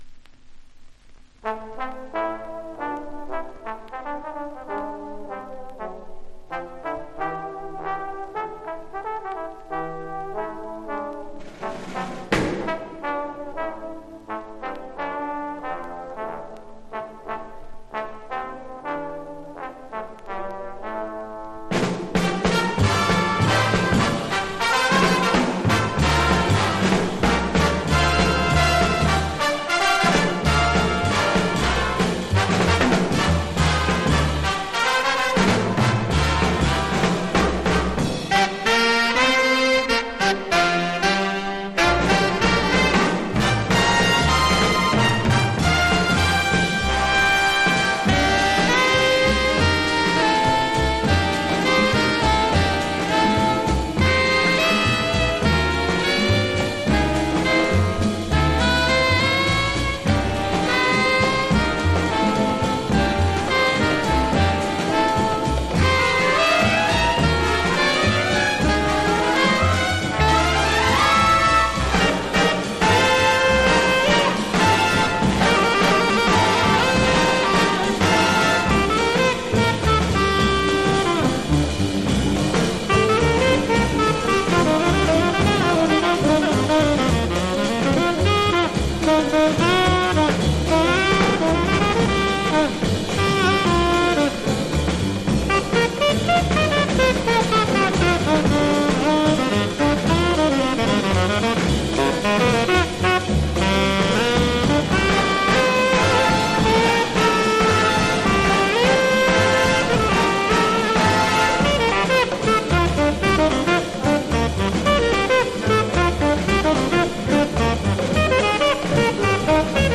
（MONO針で聴くとほとんどノイズでません）
Genre US JAZZ